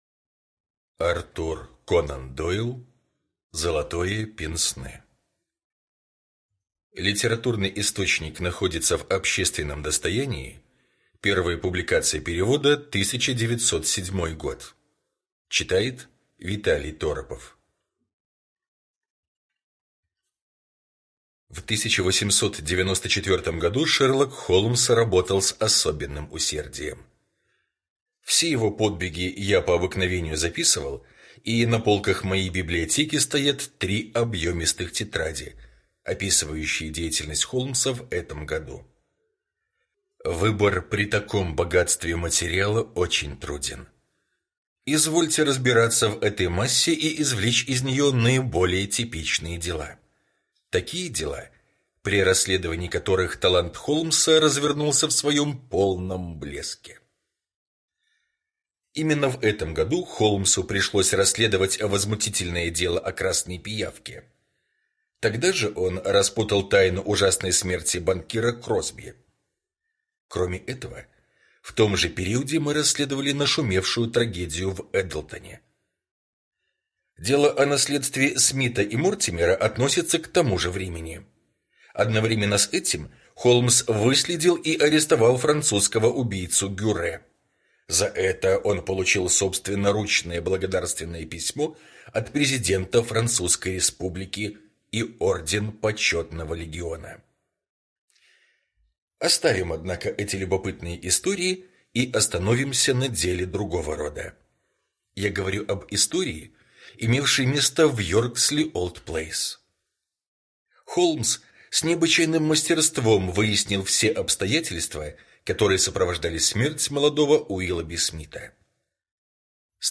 Золотое пенсне — слушать аудиосказку Артур Конан Дойл бесплатно онлайн